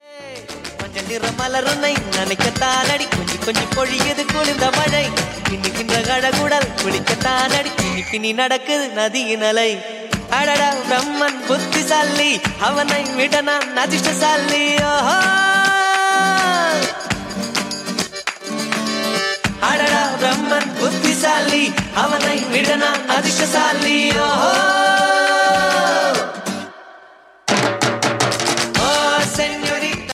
tamil ringtone
dance ringtone